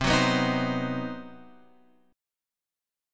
C Minor Major 7th Flat 5th